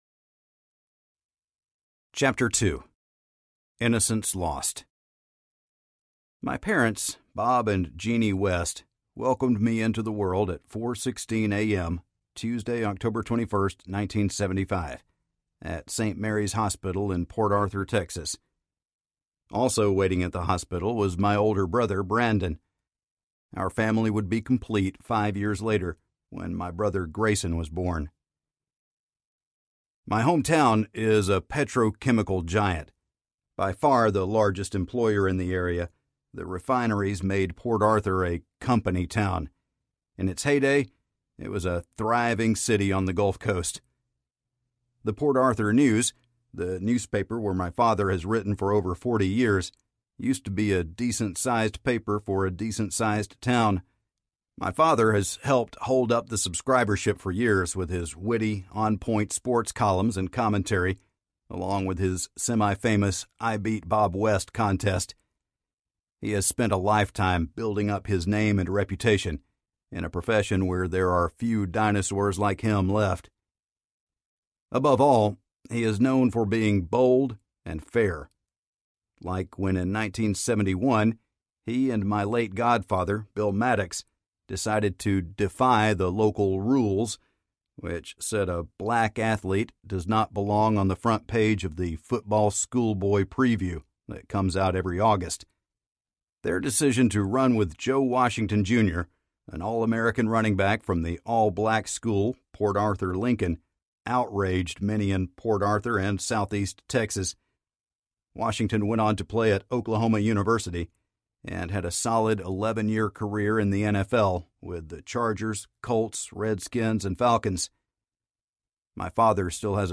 The Change Agent Audiobook
Narrator